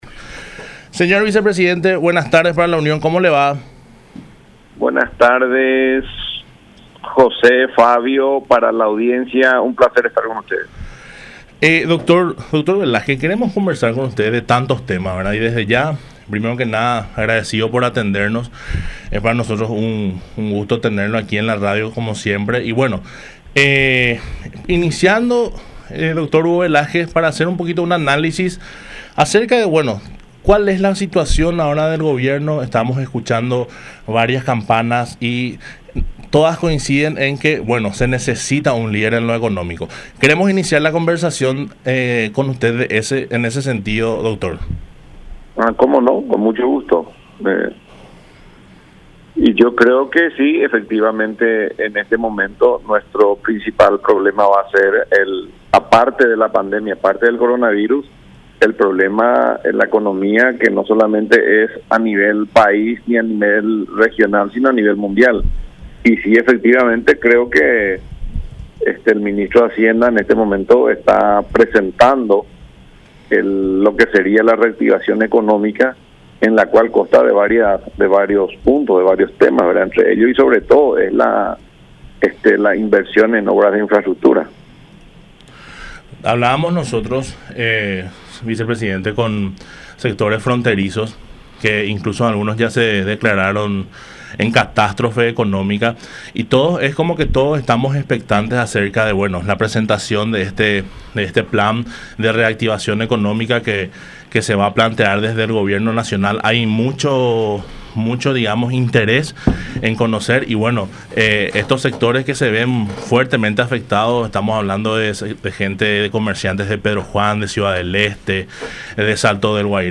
Hugo Velázquez, vicepresidente de la República en diálogo con Unión R800 AM se refirió a la situación económica fronteriza del país, principalmente Salto del Guaira, Pedro Juan Caballero, Ciudad del Este. Mencionó que desde el gobierno se esta buscando la manera paliar la crisis.